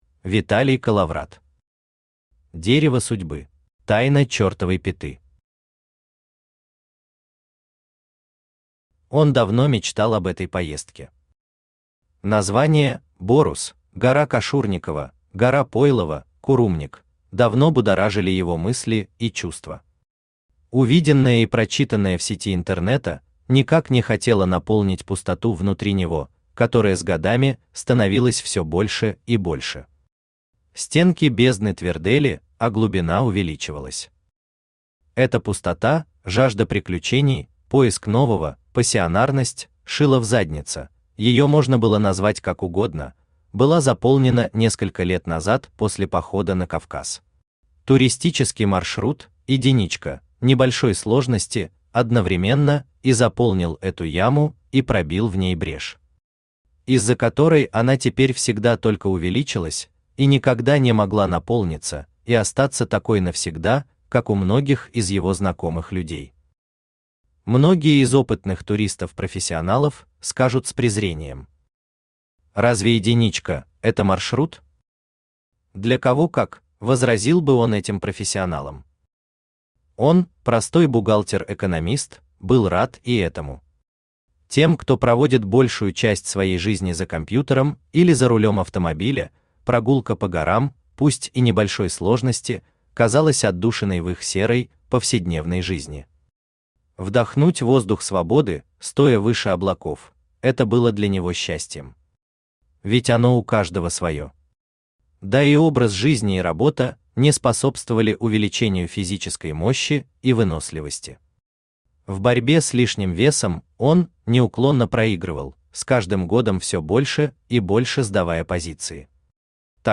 Aудиокнига Дерево Судьбы Автор Виталий Колловрат Читает аудиокнигу Авточтец ЛитРес.